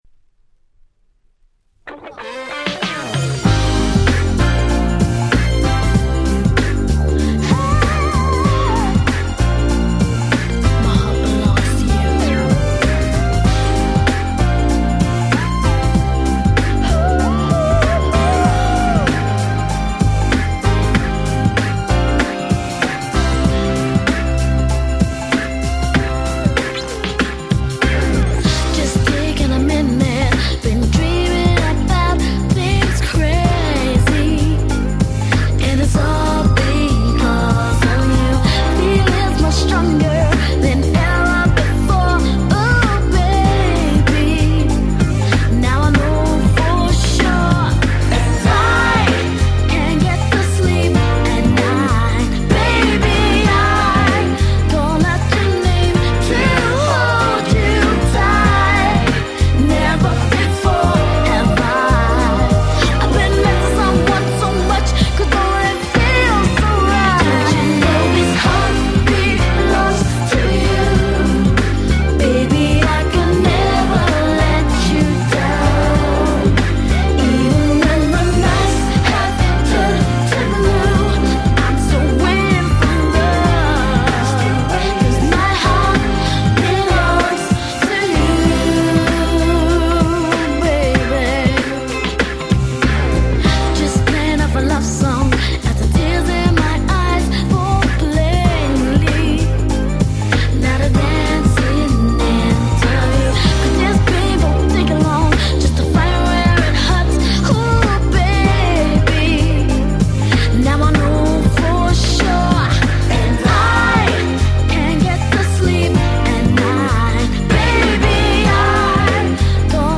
Nice UK R&B♪